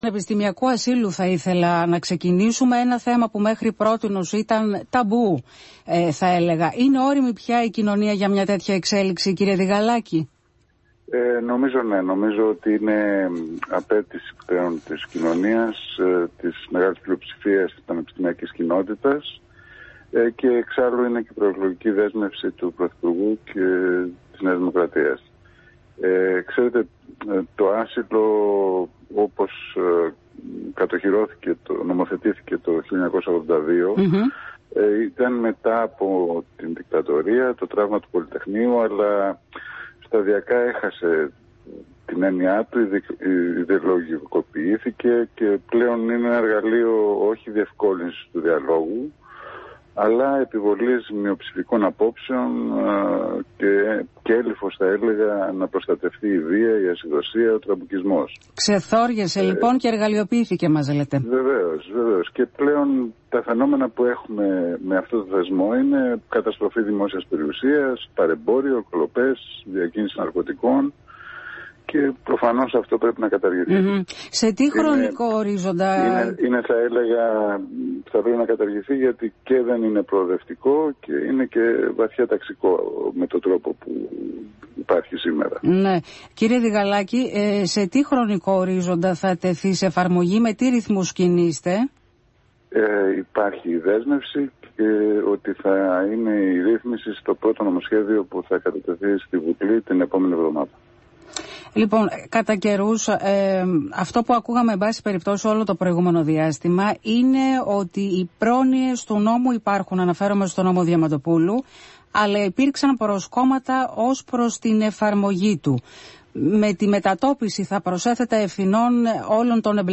δήλωσε ο υφυπουργός Παιδείας Βασίλης Διγαλάκης ,μιλώντας στον Αθήνα 9,84